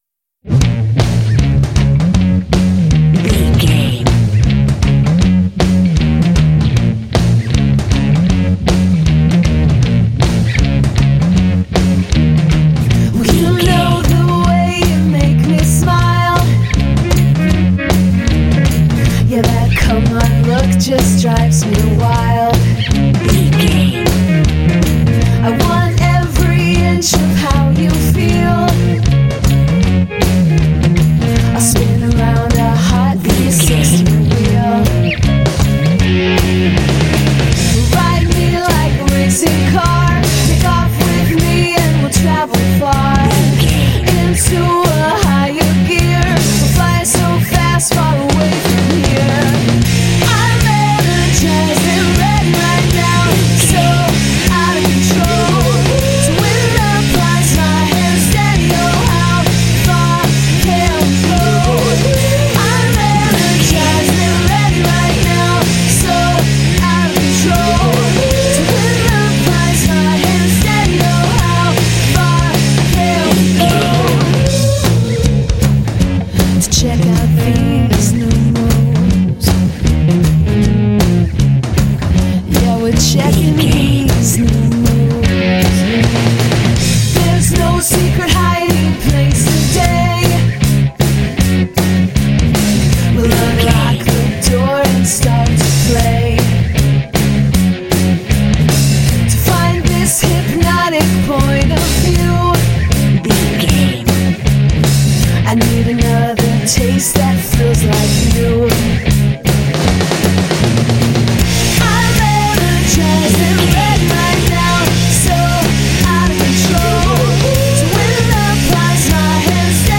Aeolian/Minor
B♭
energetic
sultry
aggressive
drums
electric guitar
bass guitar
vocals
alternative rock